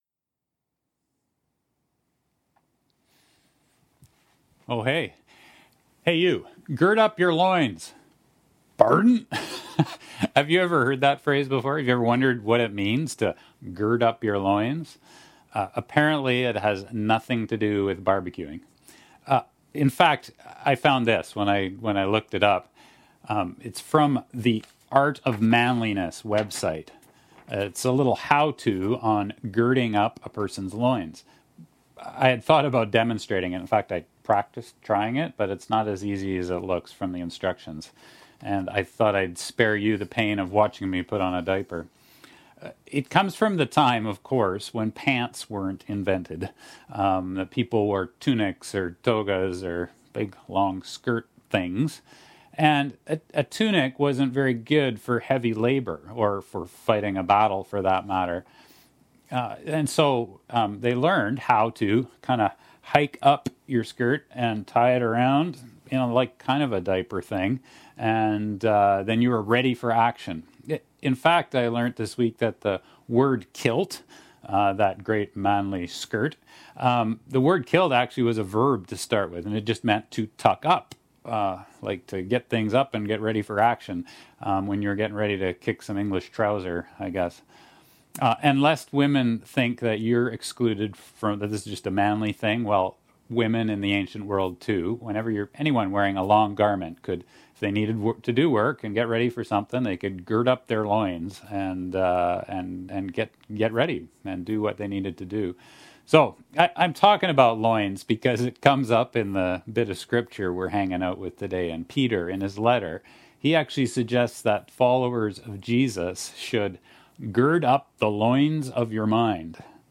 july-5-sermon-web.mp3